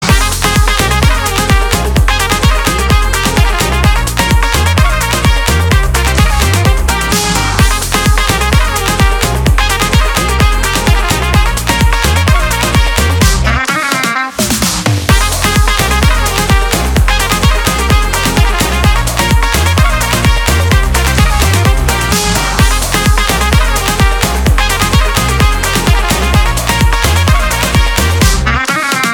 • Качество: 320, Stereo
позитивные
веселые
dance
без слов
украинские